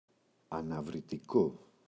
αναβρυτικό, το [anavriti’ko]